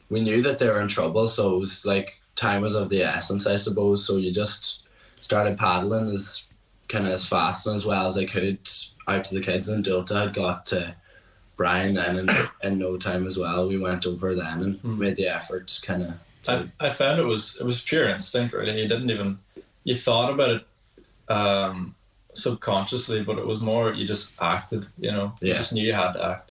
They told today’s Nine til Noon Show that they knew they had to act quickly: